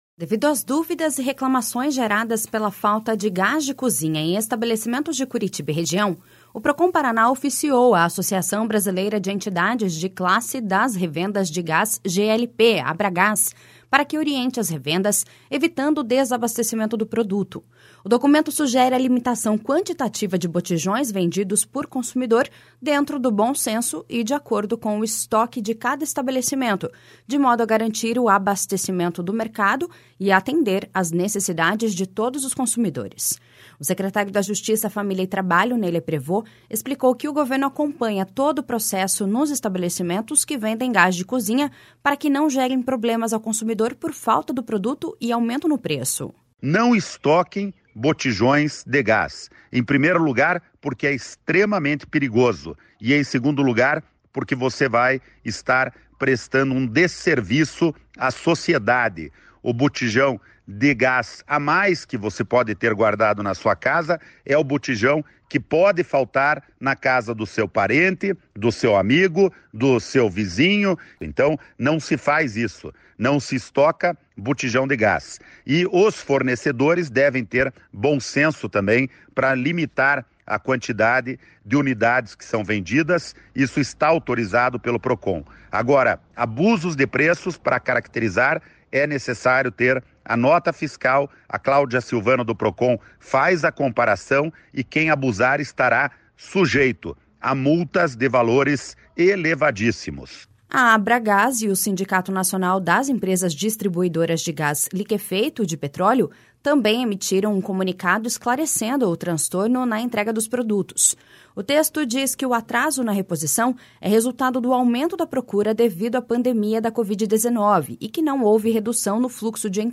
O documento sugere a limitação quantitativa de botijões vendidos por consumidor, dentro do bom senso e de acordo com o estoque de cada estabelecimento, de modo a garantir o abastecimento do mercado e atender as necessidades de todos os consumidores. O secretário da Justiça, Família e Trabalho, Ney Leprevost, explicou que o Governo acompanha todo o processo nos estabelecimentos que vendem gás de cozinha para que não gerem problemas ao consumidor por falta do produto e aumento no preço.// SONORA NEY LEPREVOST.//